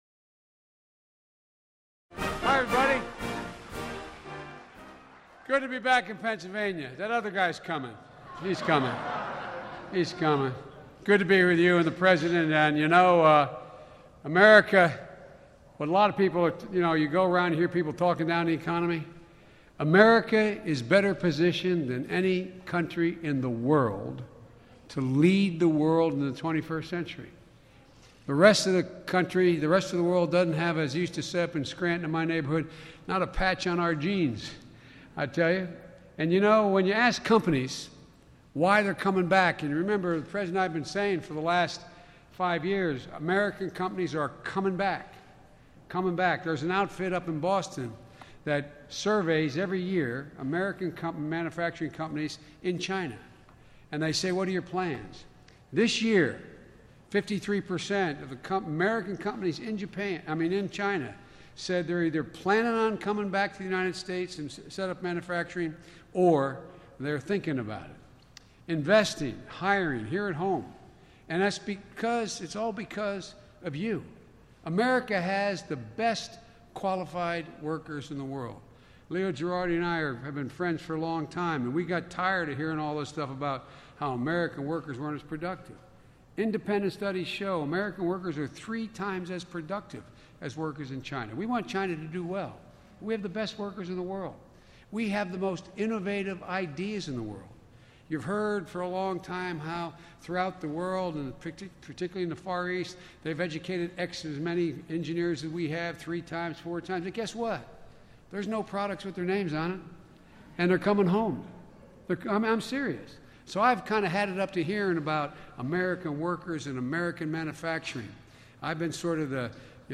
U.S. President Obama and Vice President Biden deliver remarks on the importance of jobs-driven skills training in a 21st century economy